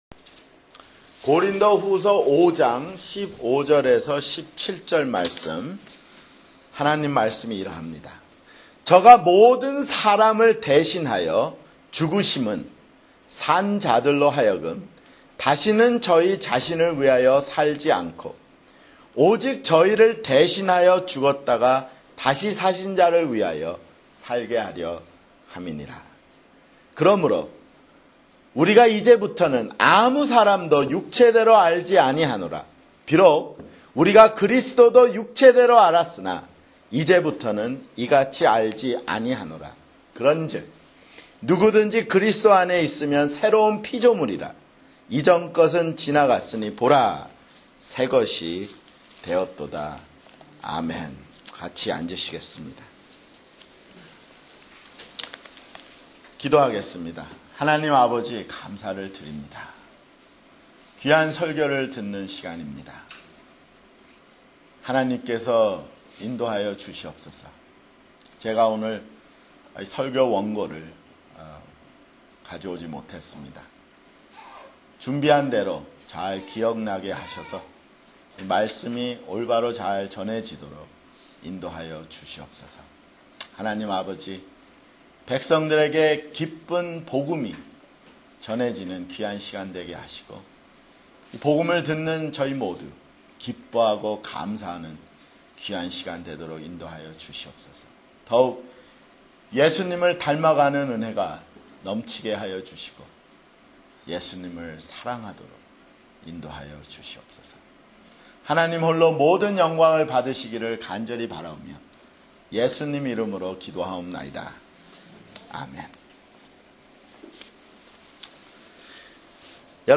[주일설교] 고린도후서 (30)